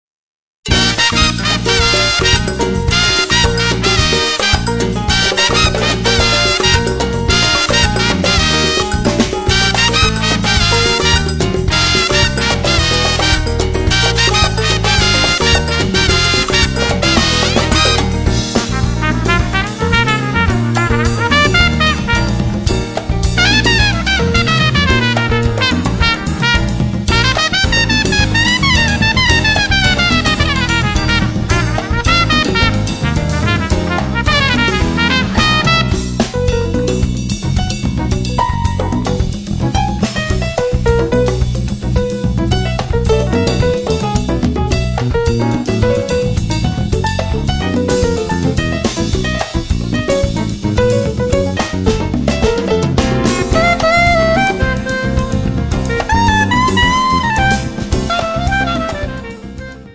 tromba, flicorno